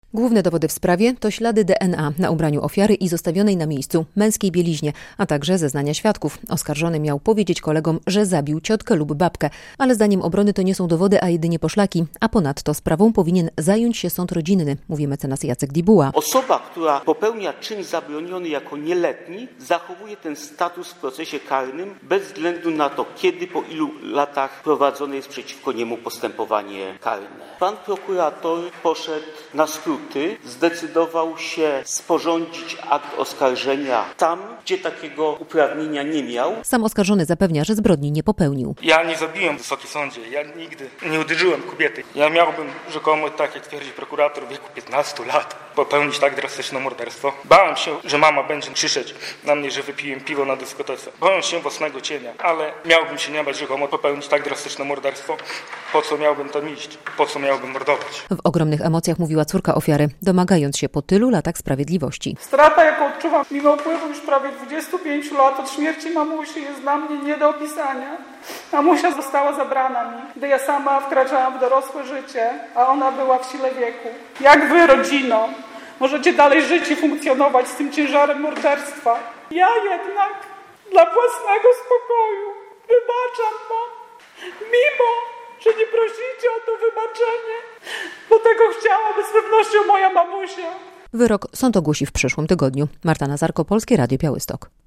Proces apelacyjny w sprawie o zabójstwo sprzed 25 lat - relacja